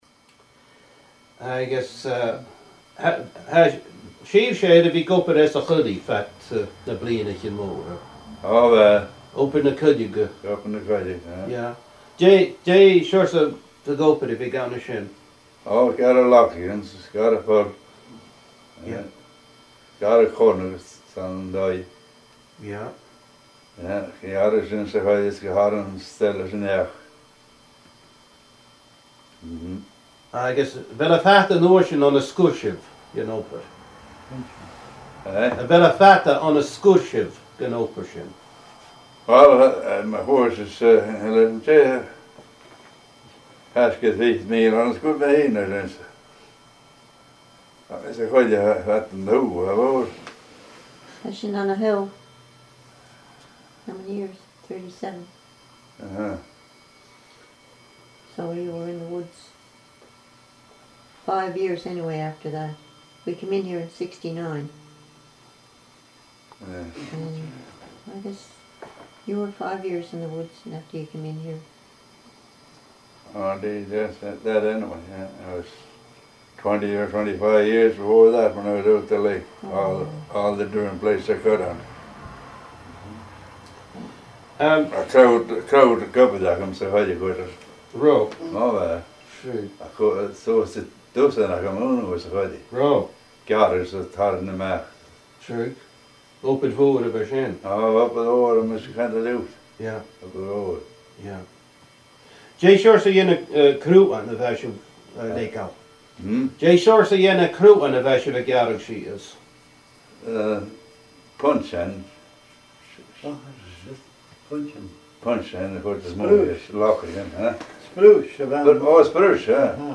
An Neach-agallaimh